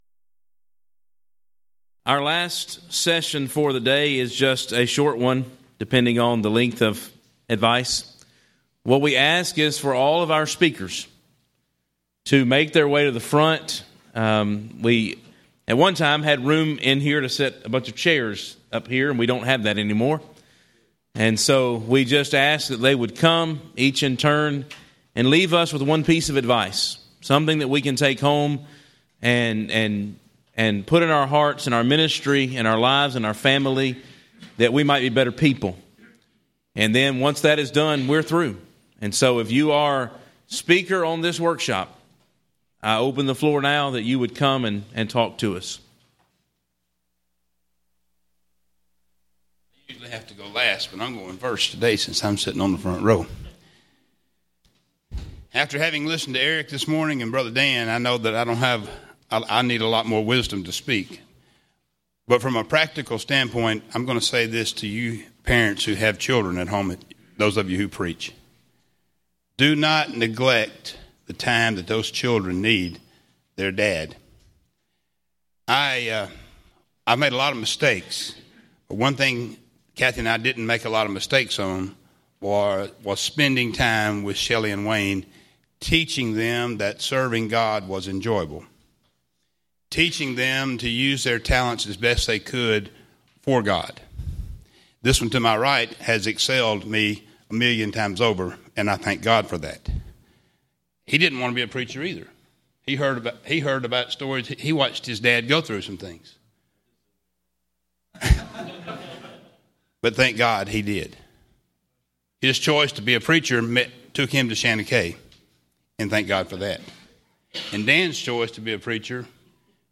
Title: My One Piece Of Advice To Today's Preachers Speaker(s): Various
Event: 2014 Focal Point Theme/Title: Preacher's Workshop